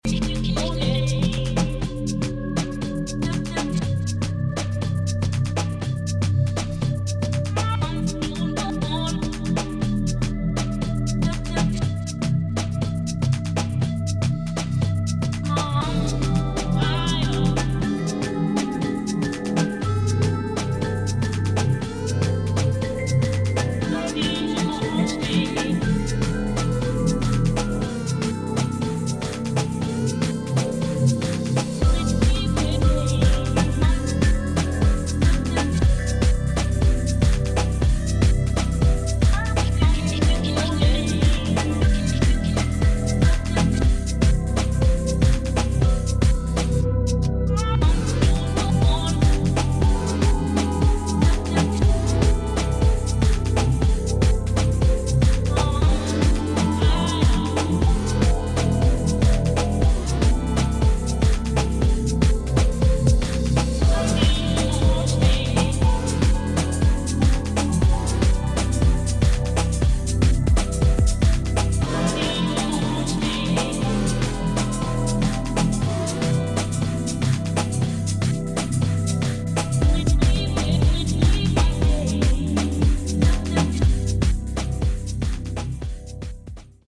DUBSTEP | BASS
[ BASS ]